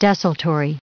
added pronounciation and merriam webster audio
211_desultory.ogg